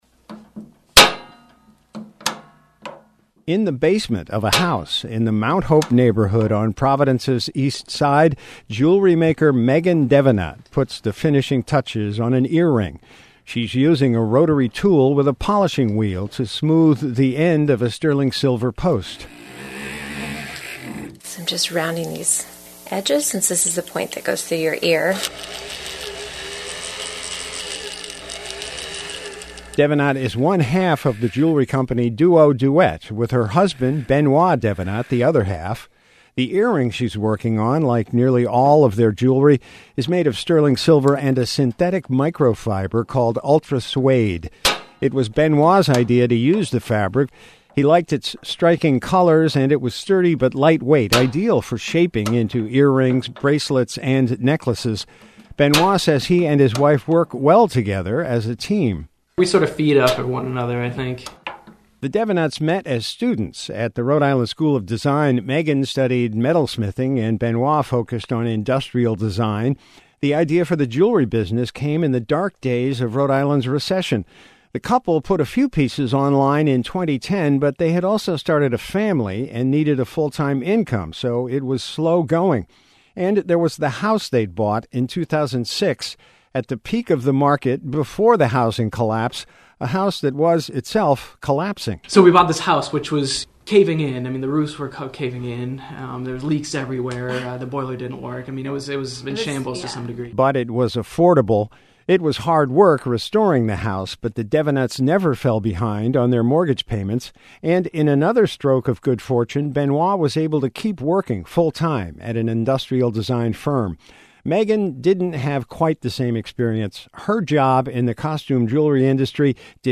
She’s using a rotary tool with a polishing wheel to smooth the end of a sterling silver post.